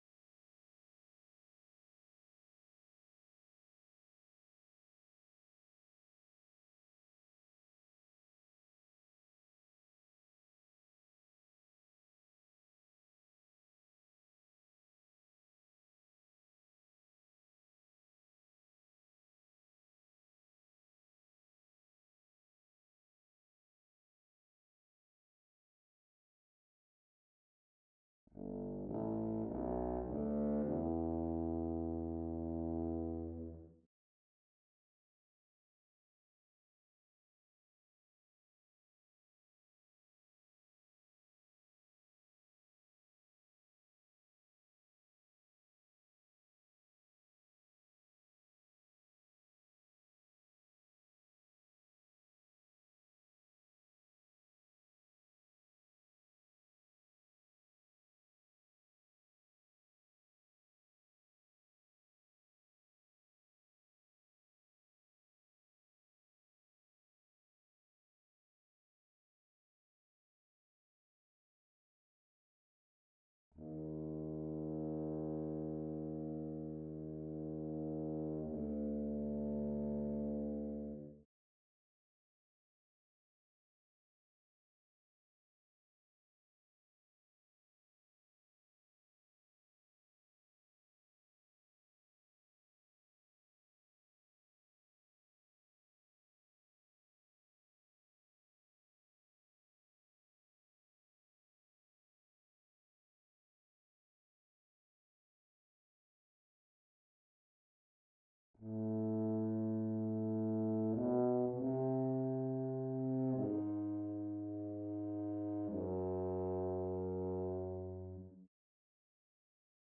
7. Tuba (Tuba/Normal)
Day_night_day-13-Tuba_0.mp3